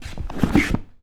Zombie Footstep Wood Sound
horror